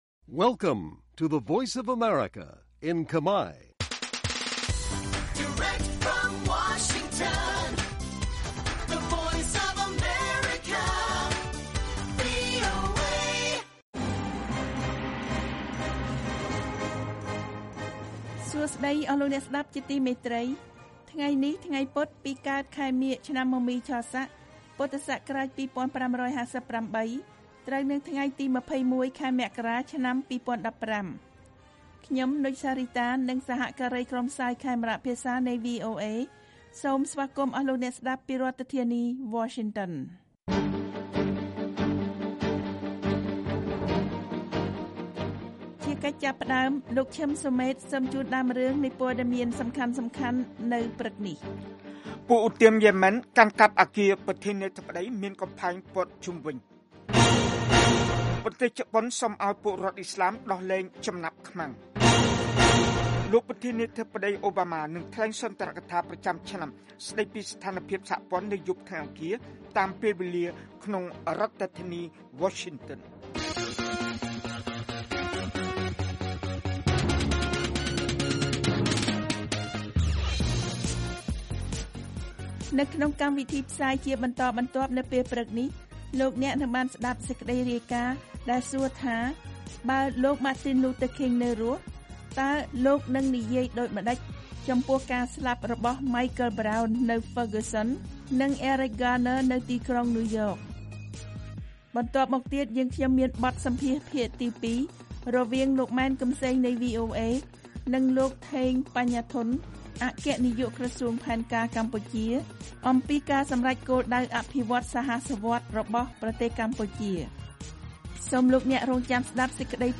This daily 30-minute Khmer language radio program brings news about Cambodia and the world, as well as background reports, feature stories, and editorial, to Khmer listeners across Cambodia.